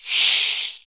SI2 CABASA.wav